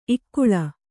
♪ ikkuḷa